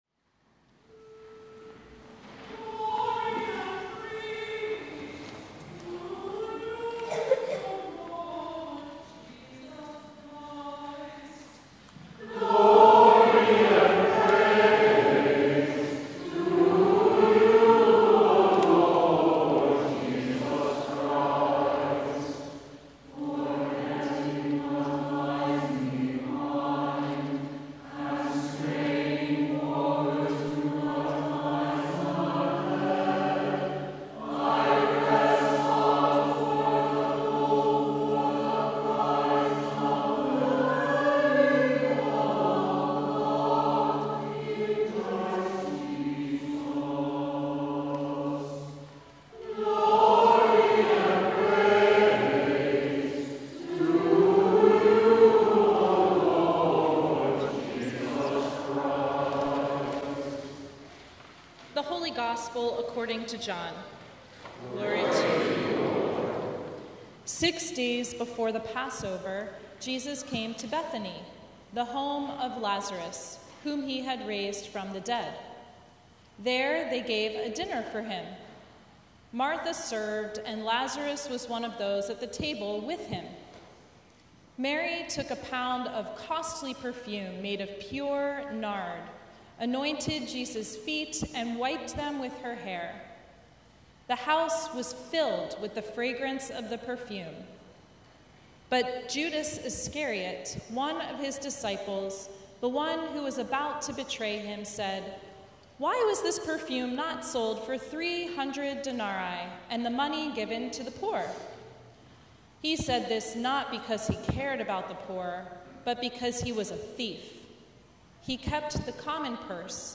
Sermon from the Fifth Sunday in Lent